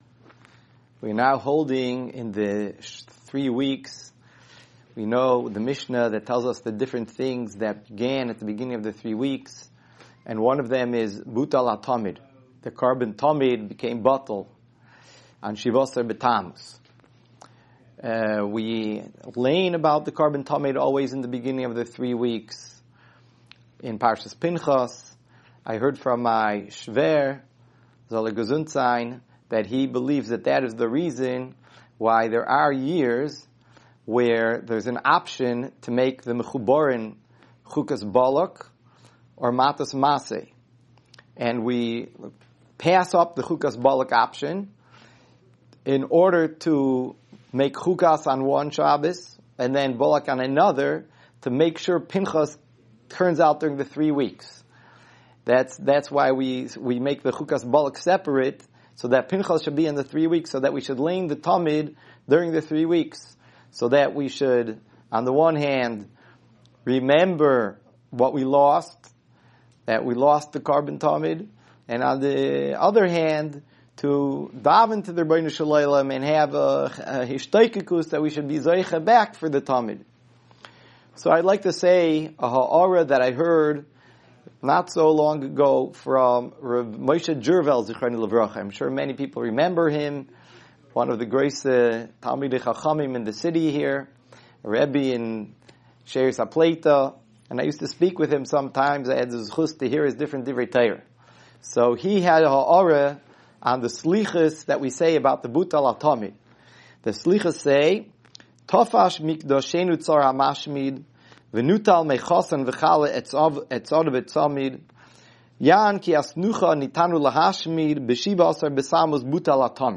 Weekly Alumni Shiur - Ner Israel Rabbinical College